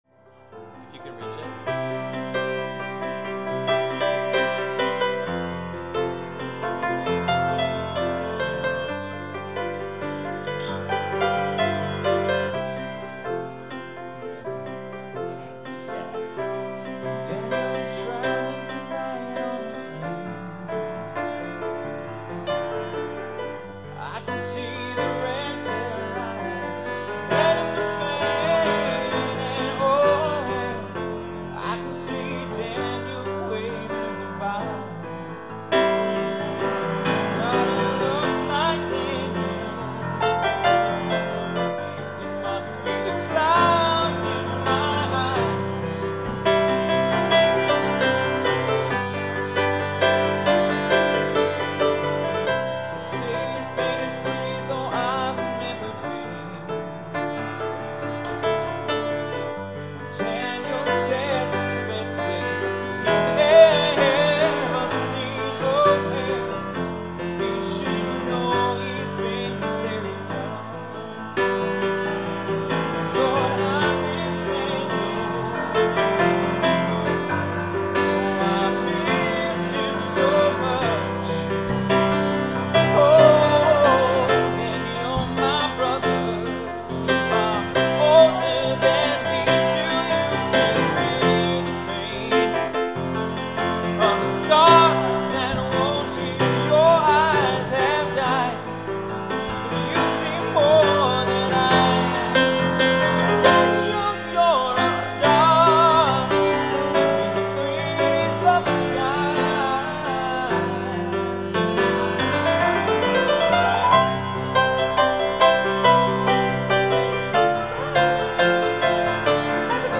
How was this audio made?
live at Gianna's, NYC